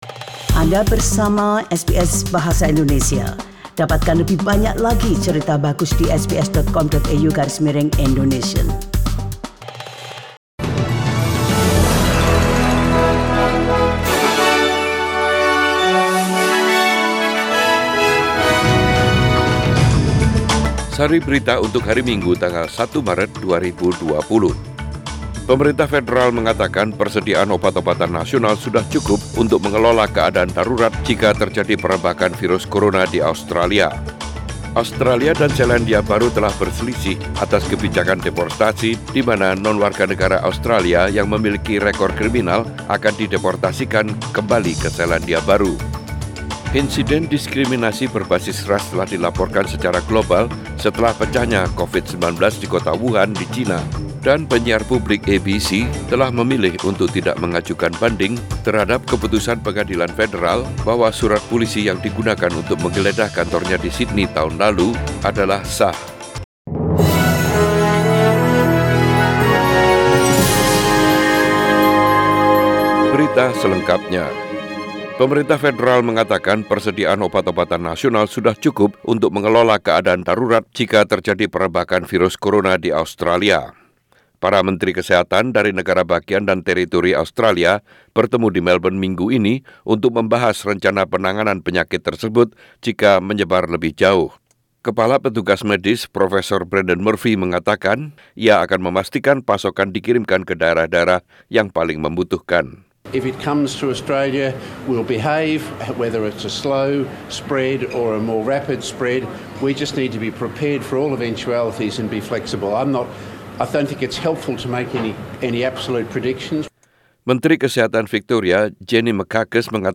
SBS Radio News in Bahasa Indonesia - 01 Maret 2020